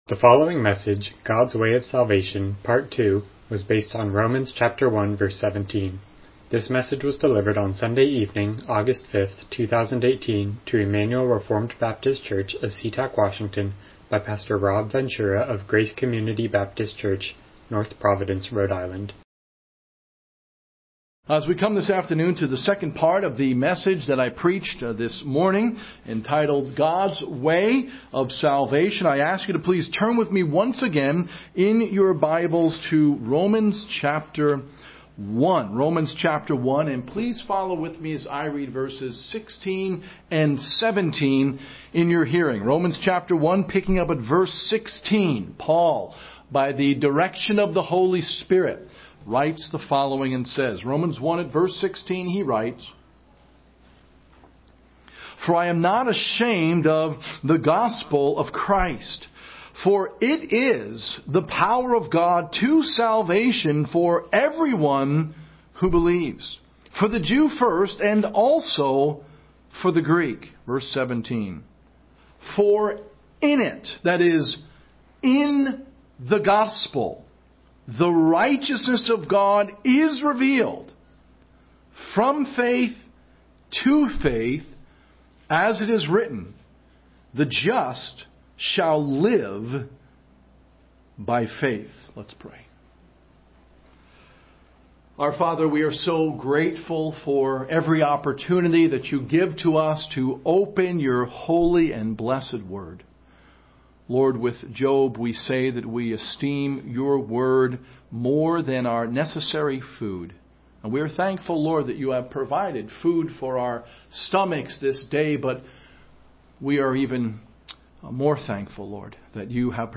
Passage: Romans 1:17 Service Type: Evening Worship « God’s Way of Salvation